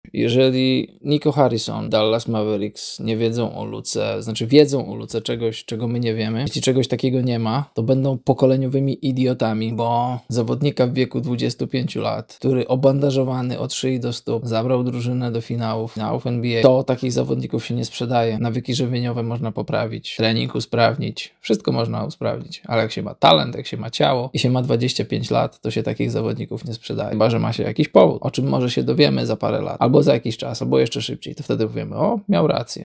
polski dziennikarz akredytowany przez NBA i FIBA